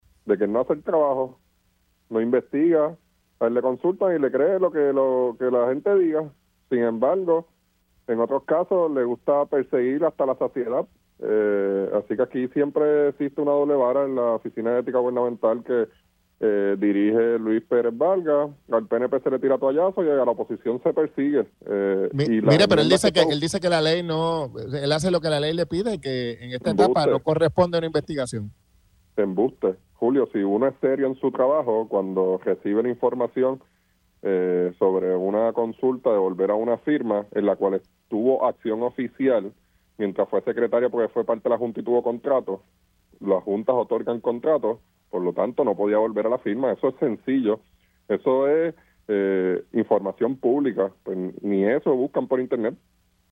417-HECTOR-FERRER-PORTAVOZ-PPD-EN-CAMARA-LUIS-PEREZ-NO-HACE-SU-TRABAJO-EN-LA-OFICINA-DE-ETICA.mp3